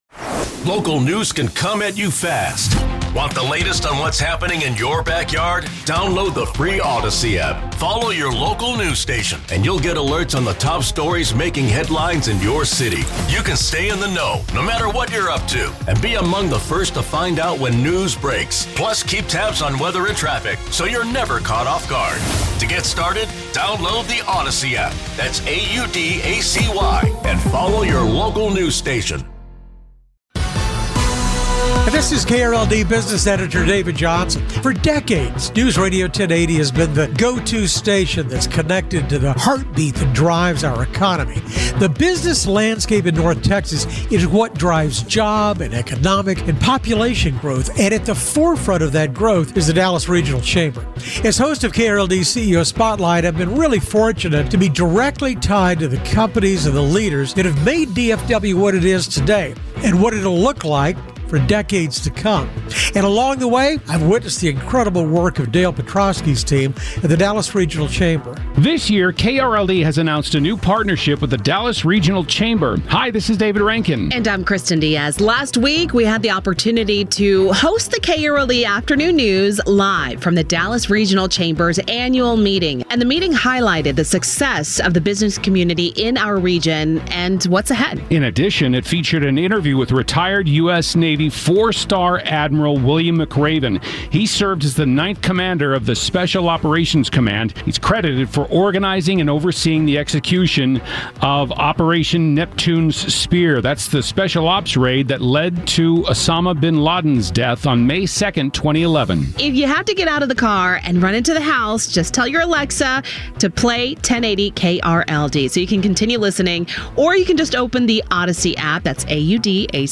Admiral William H. McRaven addresses the Dallas Regional Chamber at its annual meeting last week.